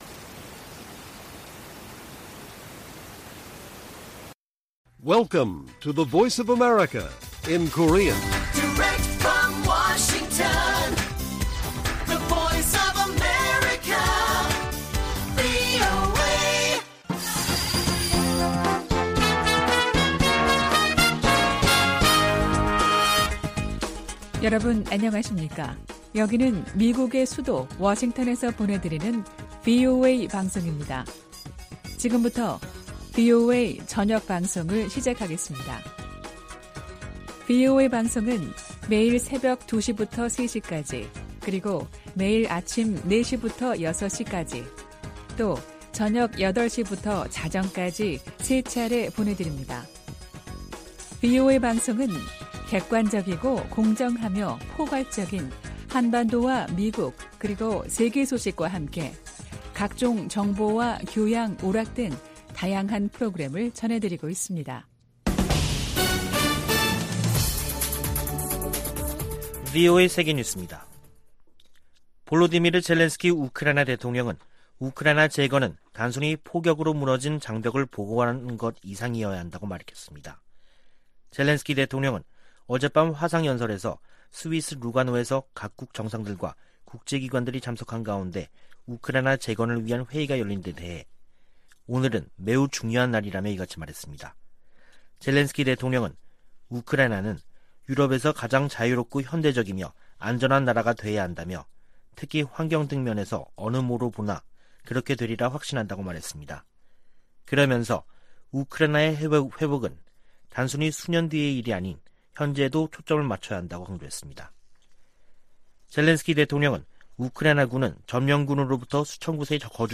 VOA 한국어 간판 뉴스 프로그램 '뉴스 투데이', 2022년 7월 5일 1부 방송입니다. 한국 국방부는 5일 미 공군 스텔스 전투기 F-35A 6대가 한반도에 전개됐다고 밝혔습니다. 북한의 7차 핵실험 가능성이 제기되는 가운데 미 공군 특수 정찰기들이 한반도와 일본, 동중국해 등에서 포착되고 있습니다. 미 하원에 타이완과 한국 등 인도태평양 동맹국들에 대한 방산물자 인도가 효율적으로 이뤄지도록 하는 법안이 발의됐습니다.